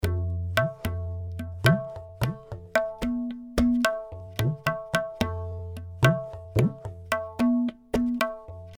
Tabla loops 55 bpm
tabla loops in 55 bpm in A scale
This is an Indian tabla drum loops (scale A), playing a variety of styles.
Played by a professional tabla player .
The tabla was recorded using one of the best microphone on the market, The AKG C-12 VR microphone . The loops are mono with no EQ, EFFECT or DYNAMICS, but exported stereo for easy Drop and play .